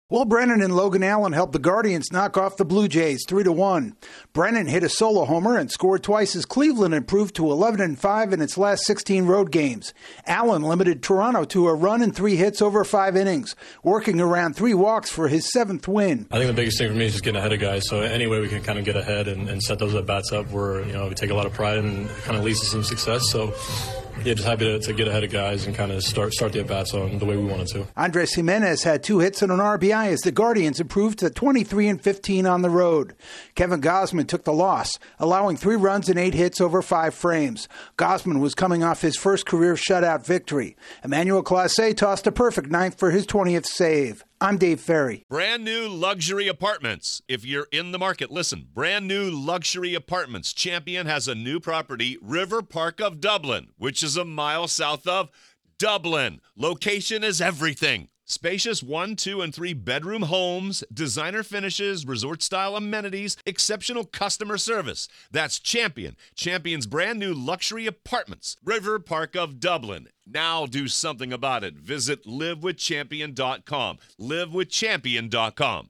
The Guardians remain road warriors following a win in Toronto. AP correspondent